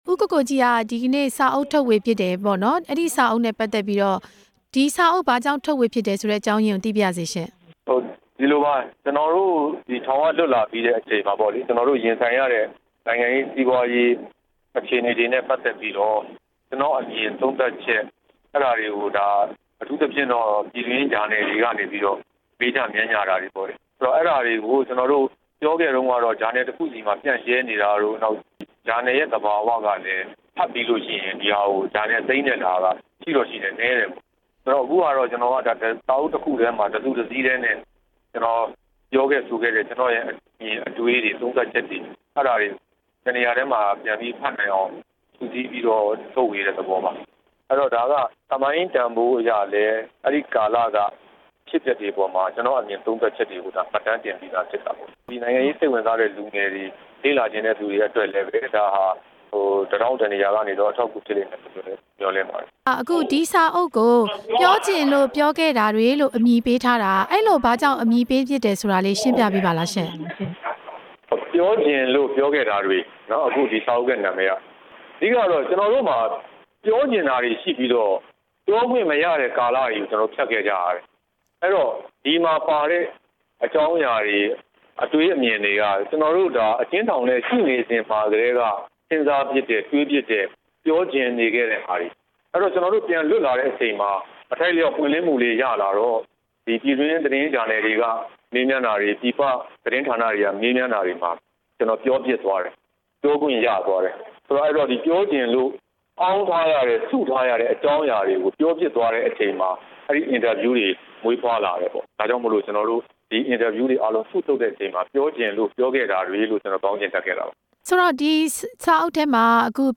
၈၈ မျိုးဆက် ကိုကိုကြီးရဲ့"ပြောချင်လို့ ပြောခဲ့တာတွေ" အပေါ်မေးမြန်းချက်